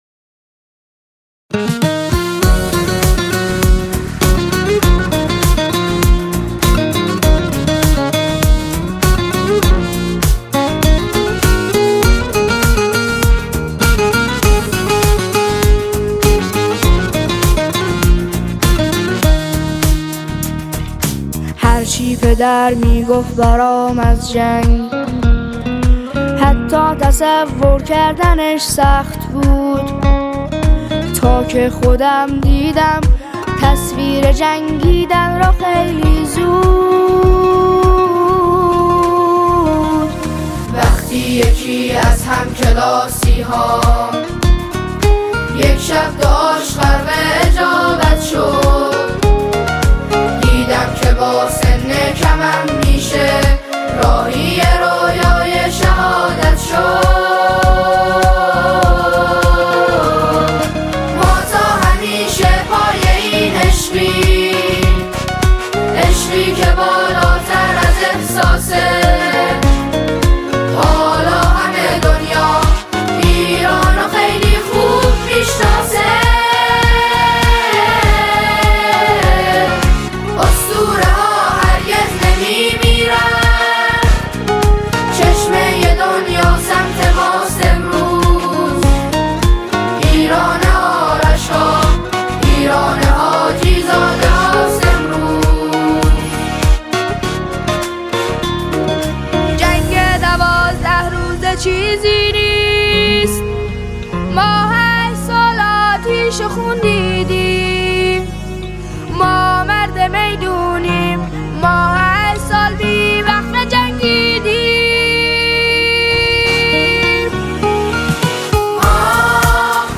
نماهنگ حماسی
ژانر: سرود